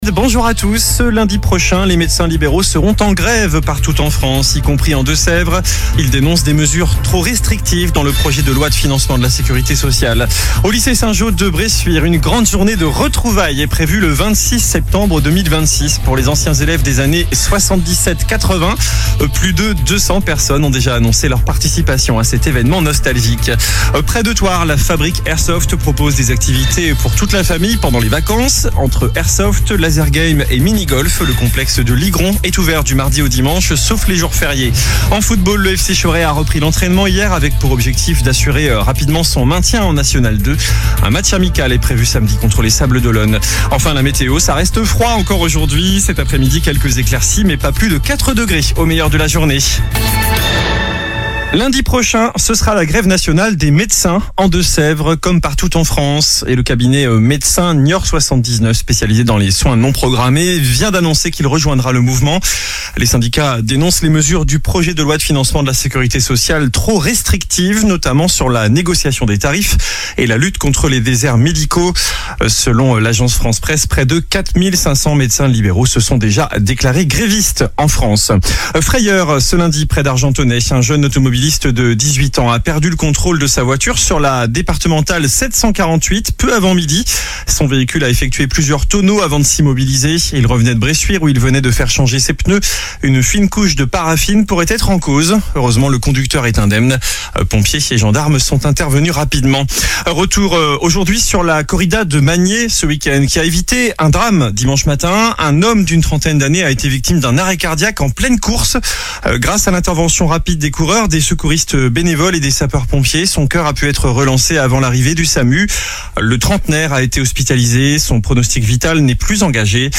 infos locales 30 décembre 2025